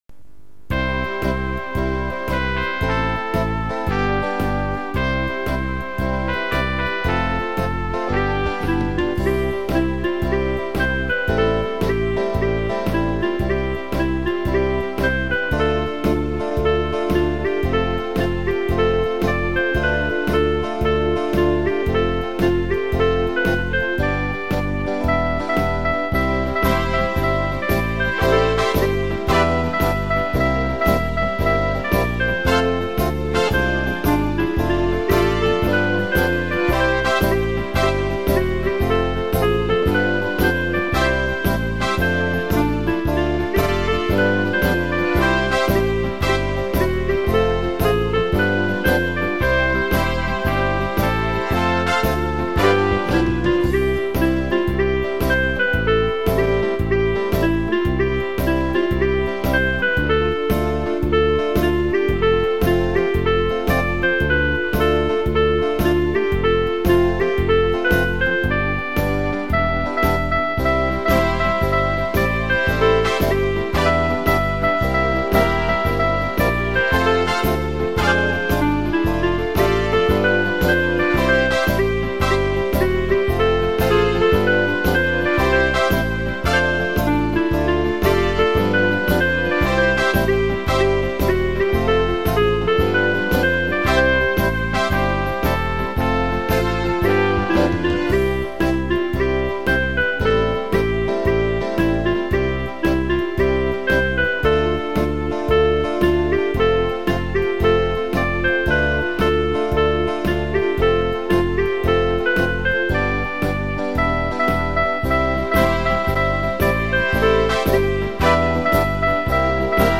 Музыкальный хостинг: /Танцевальная